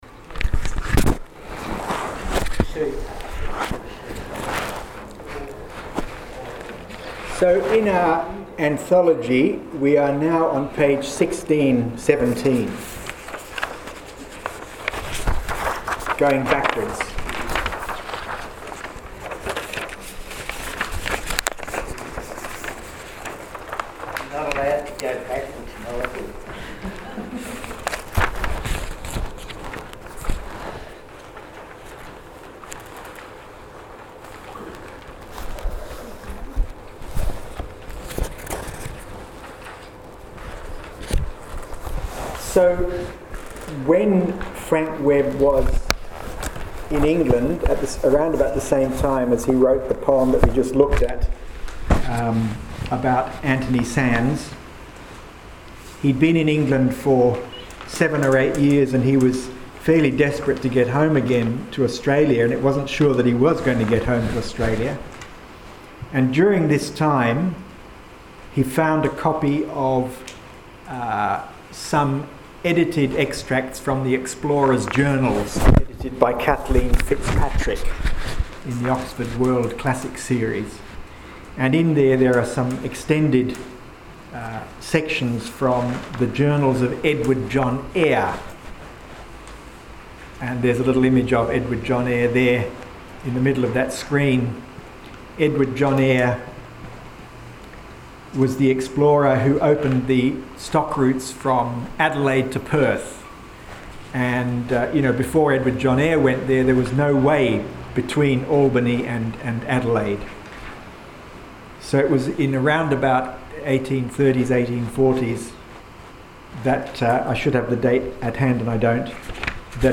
Here are the images used in today’s seminar, and the recordings of the two hours of animated, deeply engaged discussion.